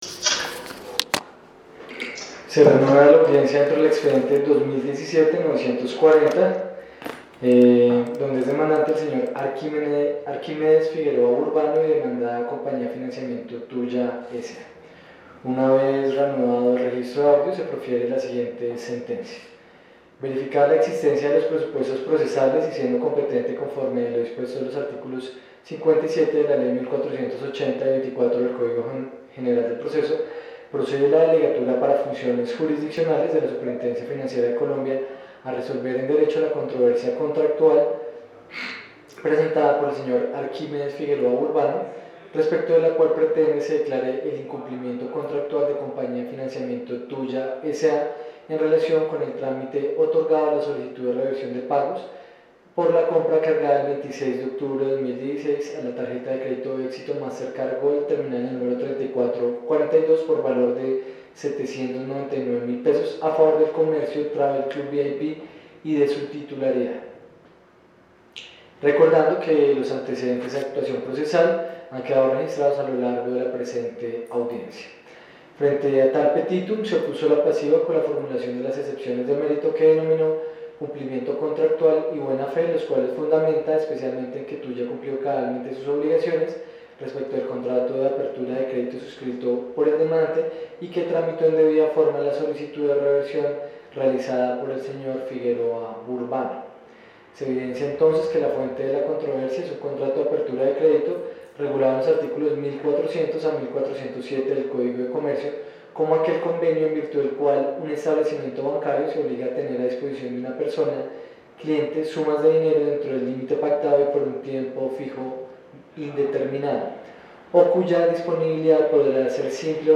Suscríbete y escucha las noticias jurídicas narradas con IA.